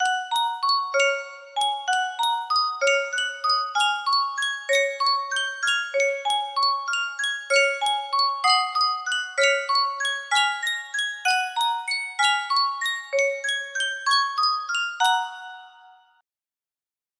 Sankyo Music Box - Tchaikovsky The Sleeping Beauty Waltz UG music box melody
Full range 60